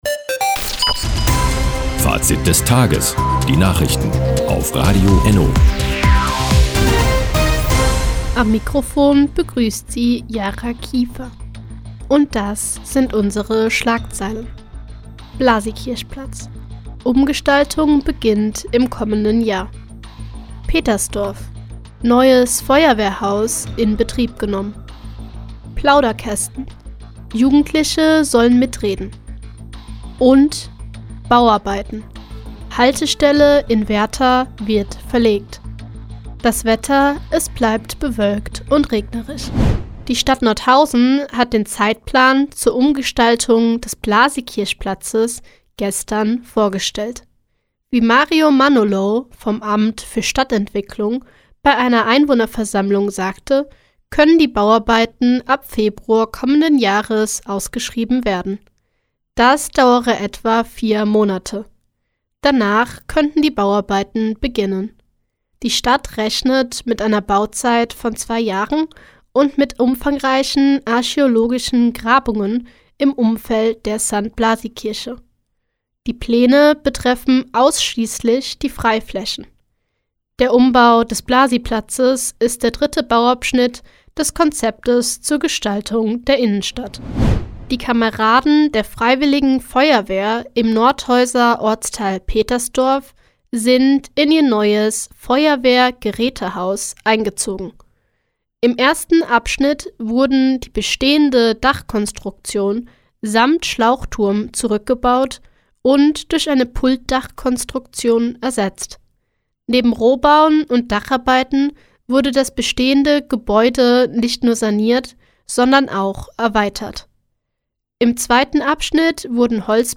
Mi, 16:30 Uhr 15.11.2023 Neues von Radio ENNO Fazit des Tages Seit Jahren kooperieren die Nordthüringer Online-Zeitungen und das Nordhäuser Bürgerradio ENNO. Die tägliche Nachrichtensendung ist jetzt hier zu hören...